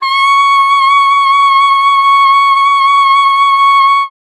42c-sax13-c#6.wav